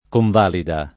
[ konv # lida ]